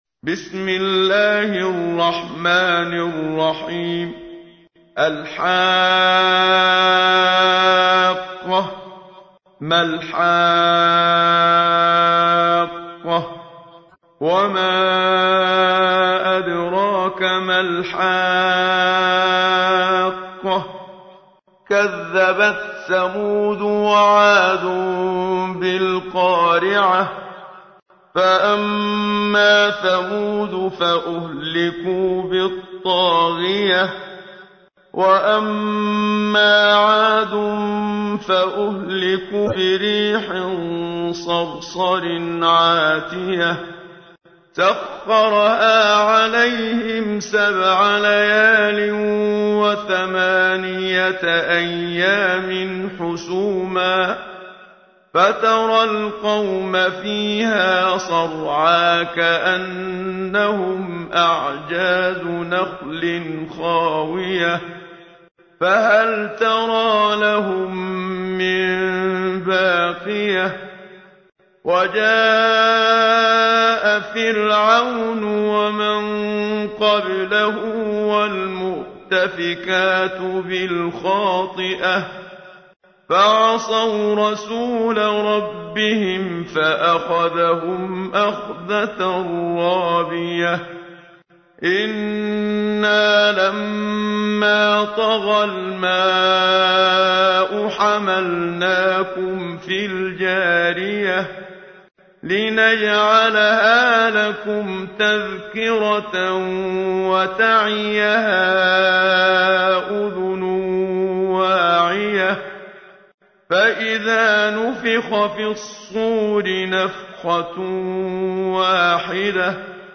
اگر می خواهید دینتان محفوظ بماند این سوره را تلاوت کنید +متن و ترجمه+ ترتیل استاد منشاوی